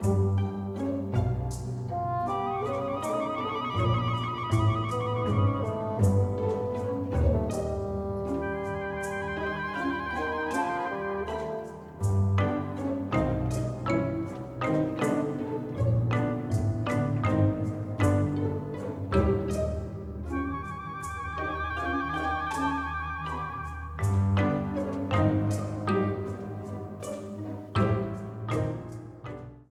A red streamer theme
Ripped from the game
clipped to 30 seconds and applied fade-out